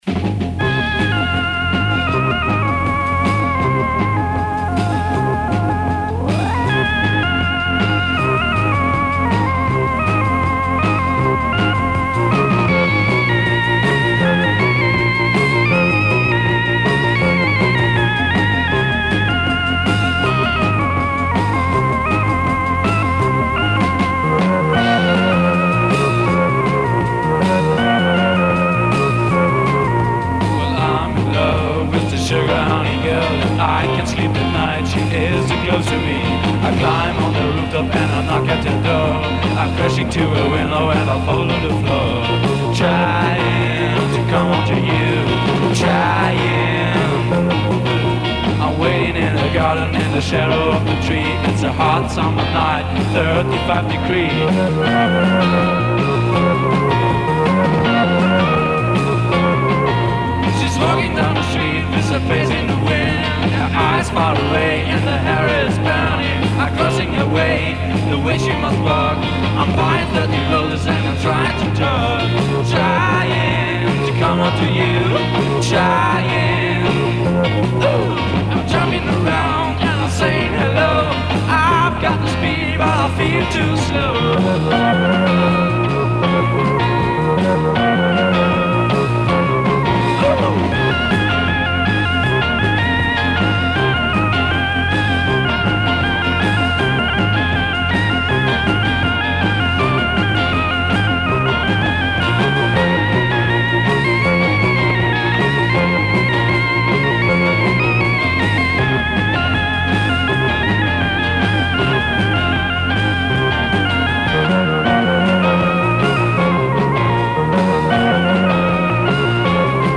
4-track-recorded